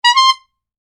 Funny Horn Sound Effect
Circus clown horn honks once. Funny and comical horn sound effect, ideal for games, TikTok videos, YouTube clips, mobile apps, or any other multimedia content.
Funny-horn-sound-effect.mp3